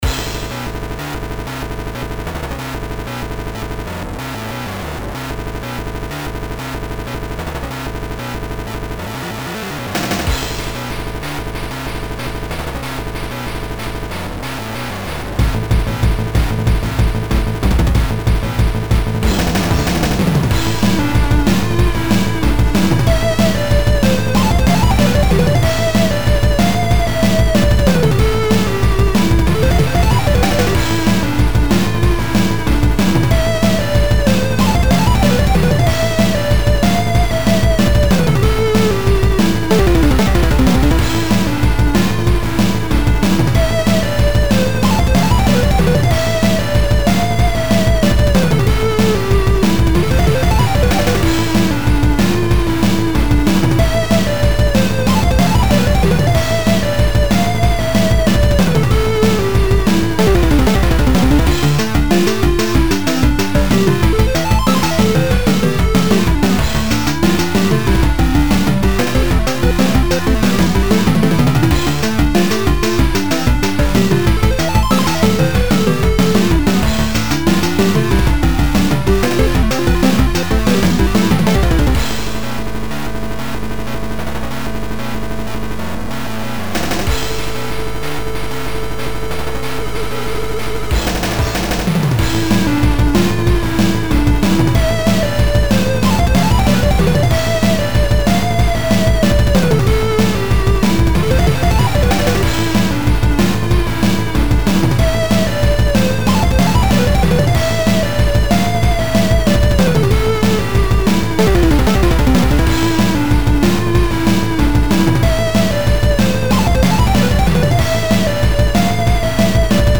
VGMM Metal! m/ (Solo track by me ) Enjoy!